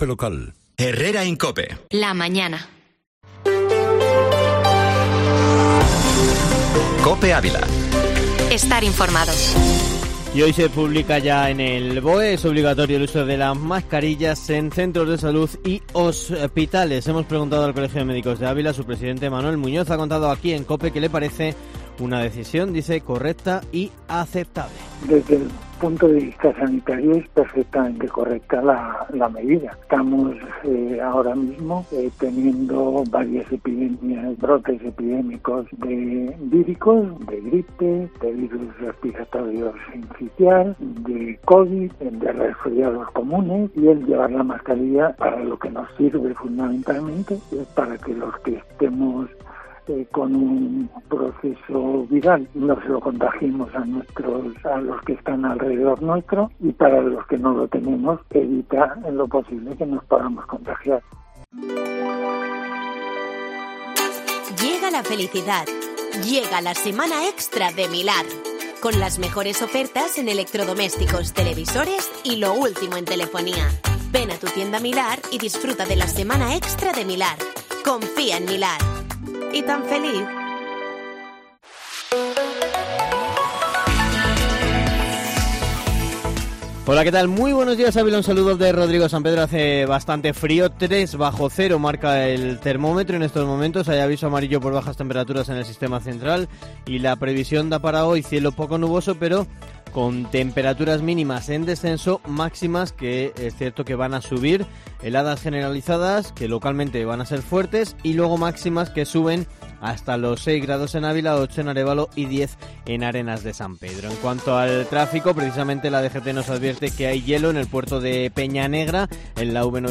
Informativo Matinal Herrera en COPE Ávila